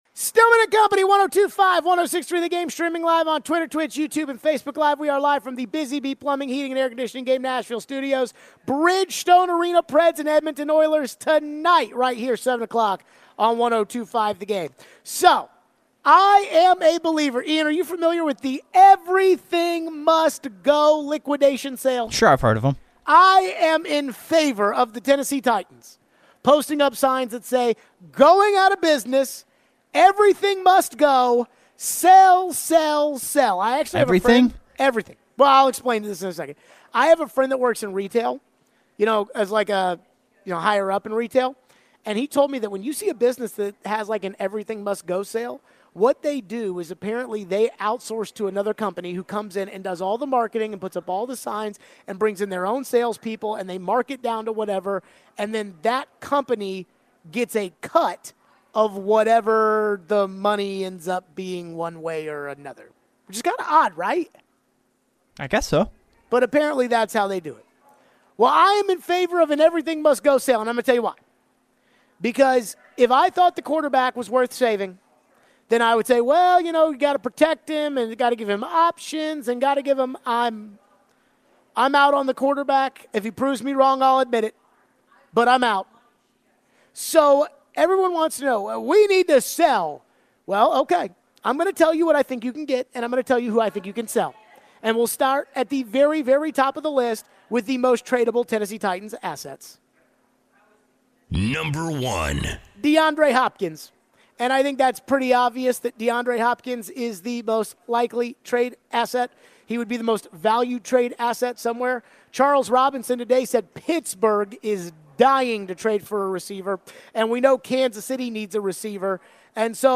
Hal Gill Interview